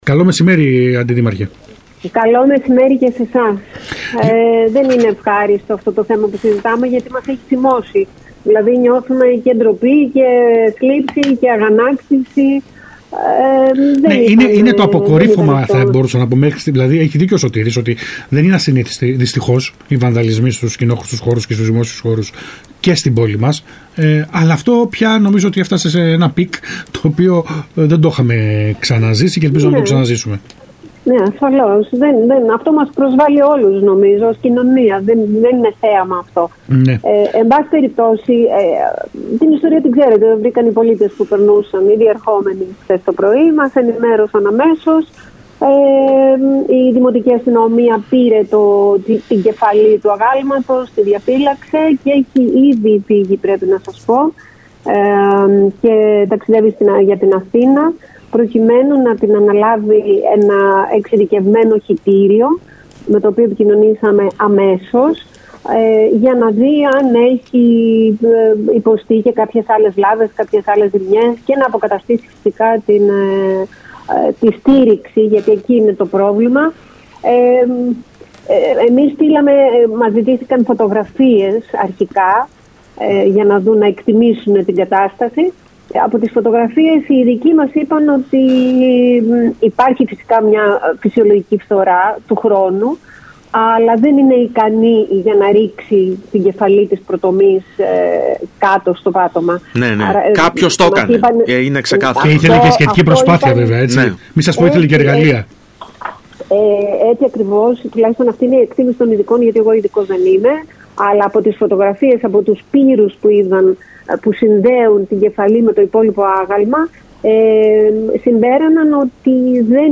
Ακούστε εδώ όσα είπε στον ΣΚΑΙ Κρήτης 92.1 η Αντιδήμαρχος Πολιτισμού Ρένα Παπαδάκη: